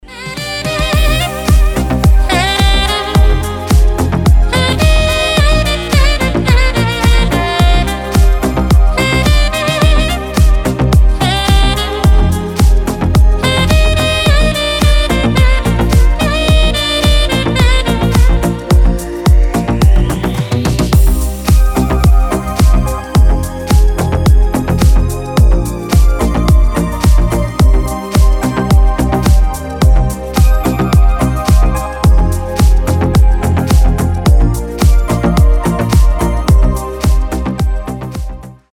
• Качество: 320, Stereo
deep house
без слов
красивая мелодия
Саксофон
Известнейшая композиция в звучании саксофона